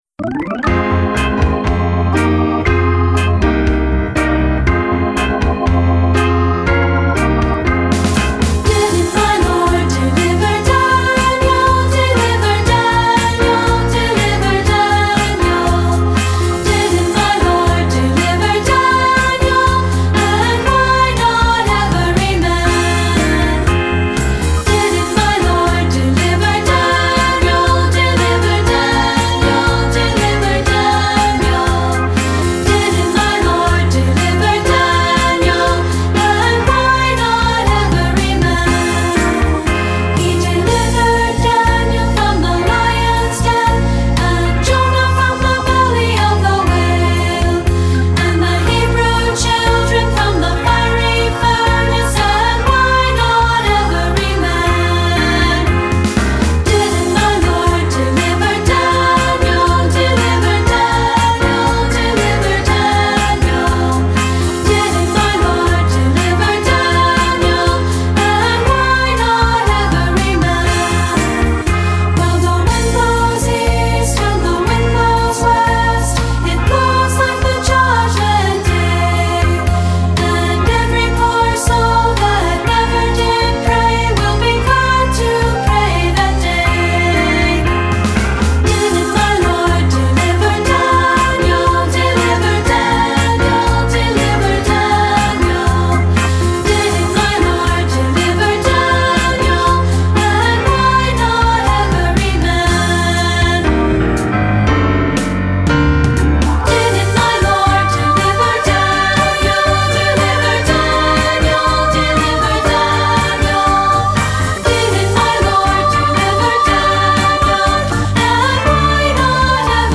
Composer: Traditional Spiritual
Voicing: Unison/2-Part